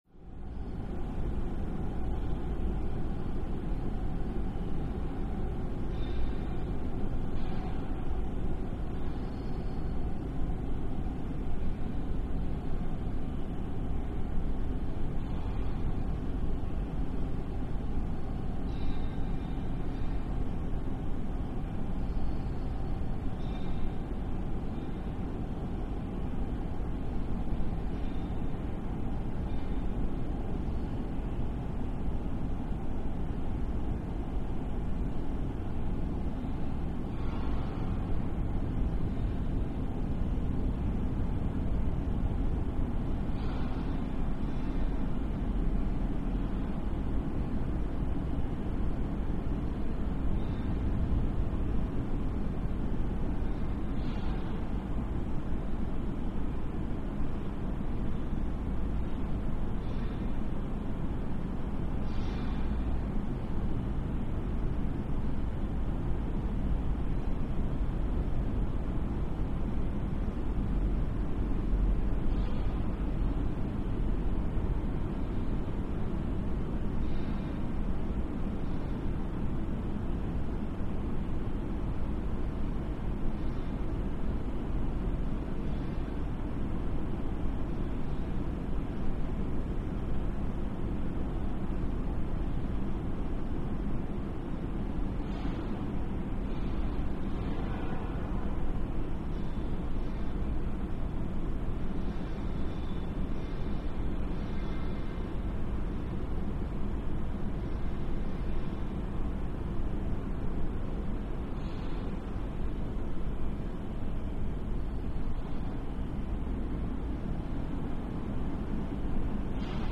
Dark Factory Ambience, Reverent Distant Machines And Impacts